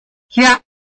臺灣客語拼音學習網-客語聽讀拼-饒平腔-入聲韻
拼音查詢：【饒平腔】hiab ~請點選不同聲調拼音聽聽看!(例字漢字部分屬參考性質)